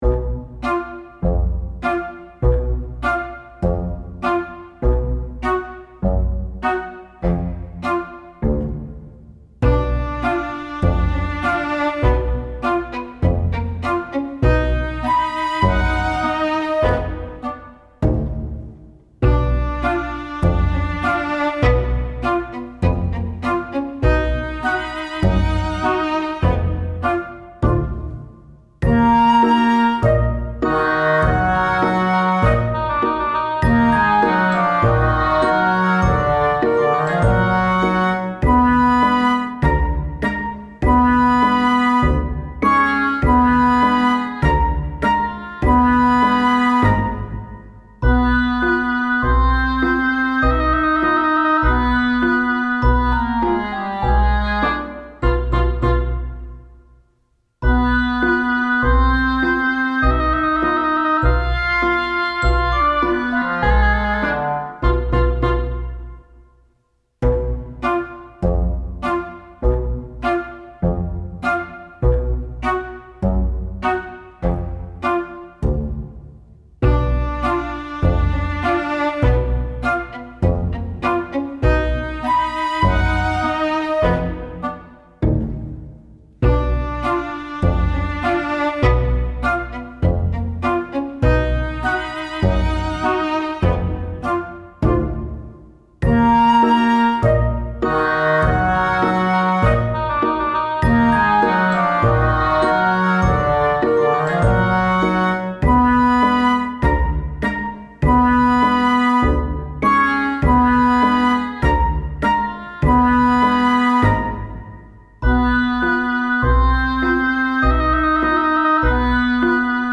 けだるい時やなんにもやる気が出ないときにぴったりです。